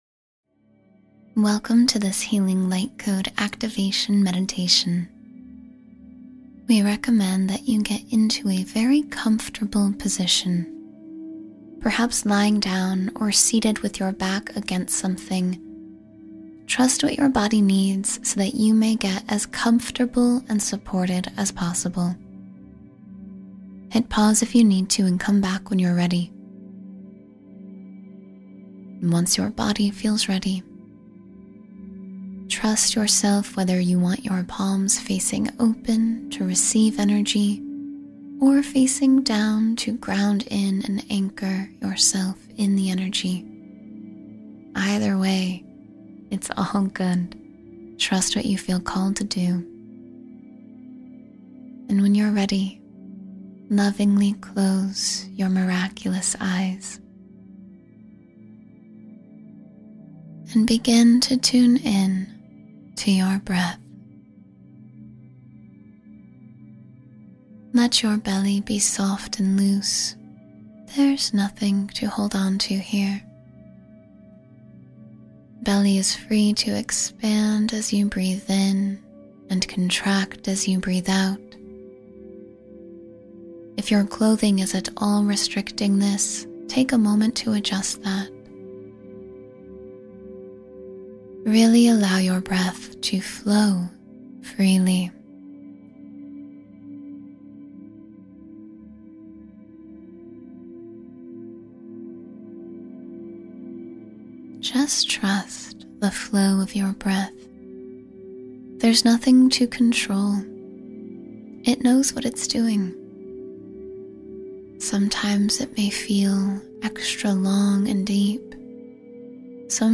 Morning I Am Affirmations to Start Your Day Strong